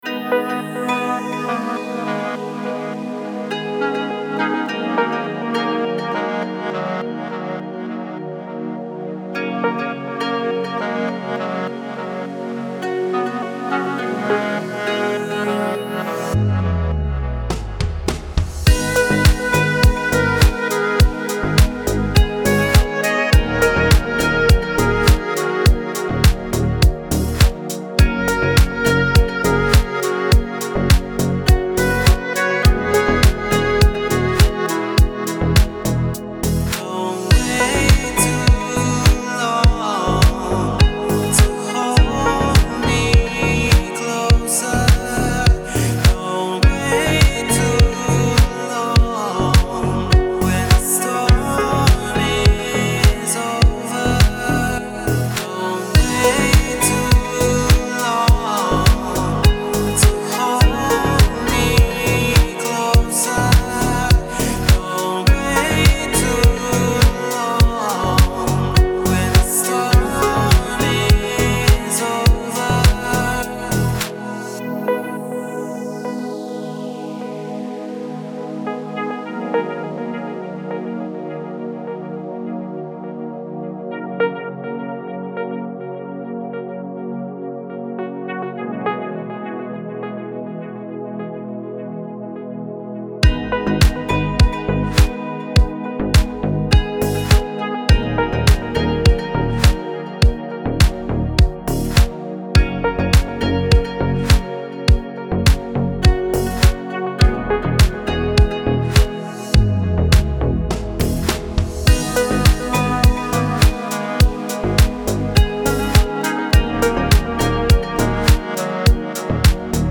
دیپ هاوس , ریتمیک آرام , موسیقی بی کلام